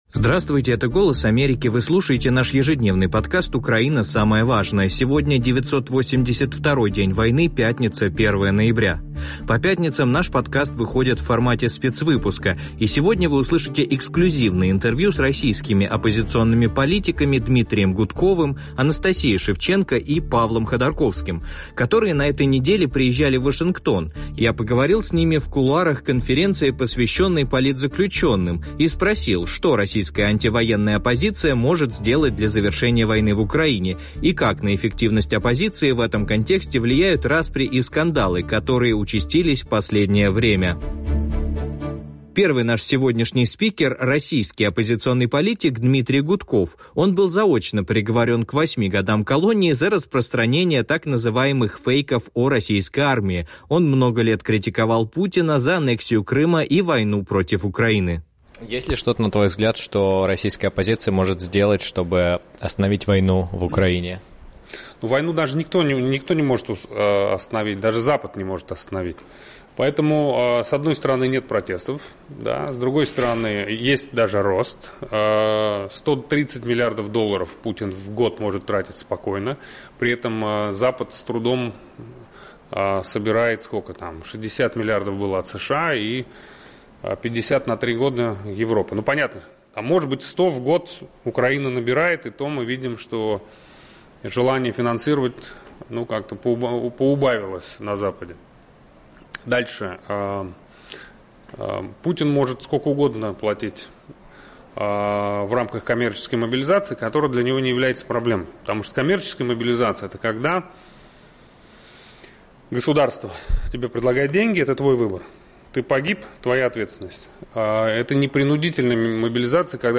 эксклюзивные интервью